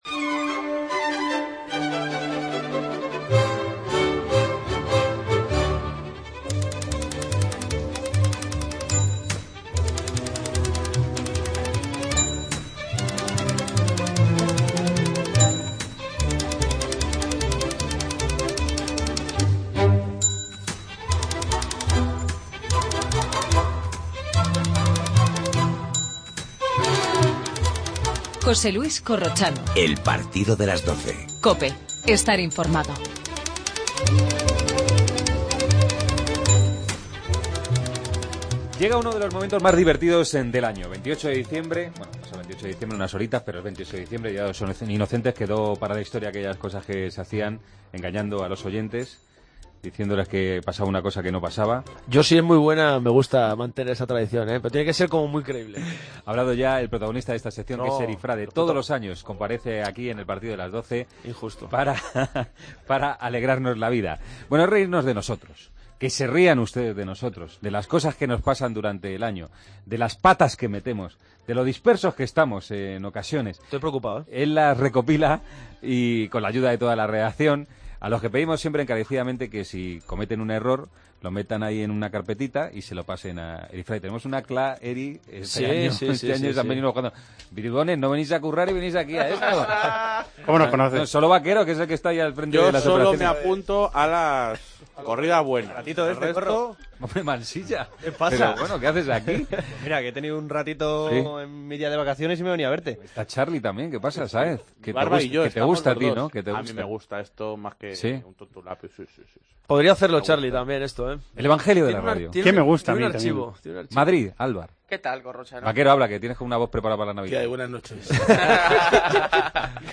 Tantas horas de deporte en directo, es lo que tiene.